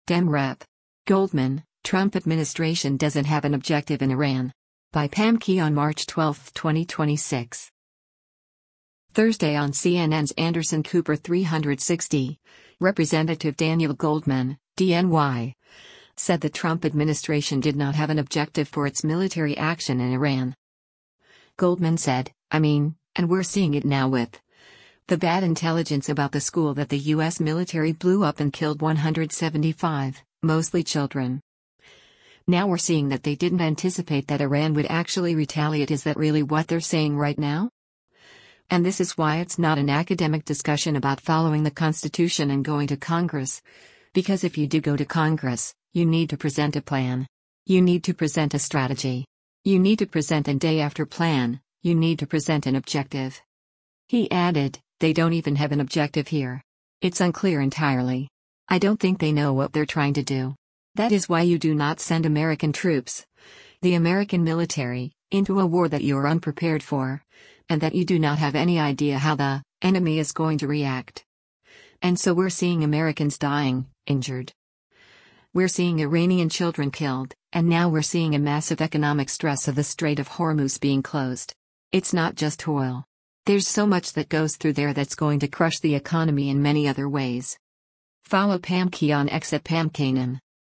Thursday on CNN’s “Anderson Cooper 360,” Rep. Daniel Goldman (D-NY) said the Trump administration did not have an objective for its military action in Iran.